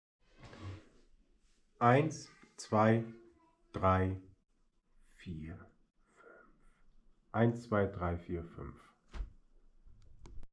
Die Feiertage hatte ich mal etwas Zeit und habe eine Vergleichsaufnahme gemacht: Abstand zur Kamera ca. 30cm im Raum.
Tonaufnahme - Gopro Max
Die GoPro scheint zu erkennen, dass gerade nicht gesprochen wird. Somit gibt es kein Grundrauschen.